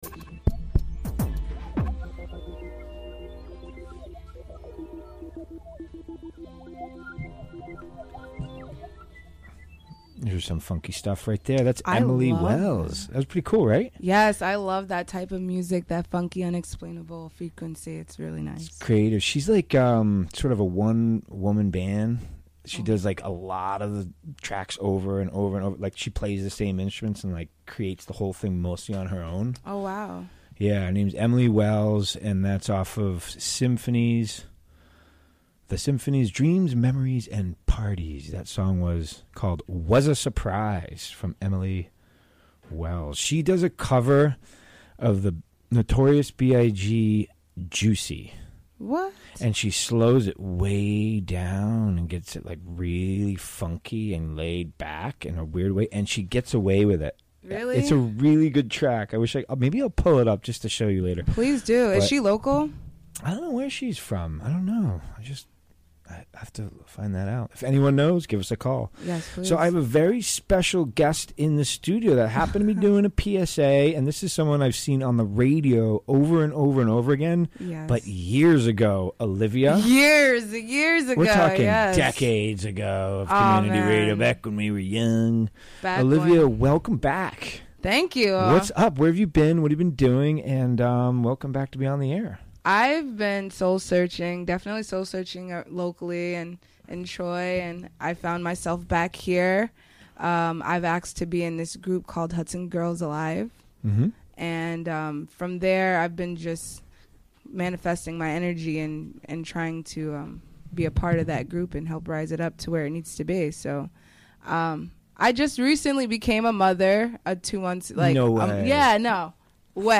Recorded during the WGXC Afternoon Show Monday, December 4, 2017.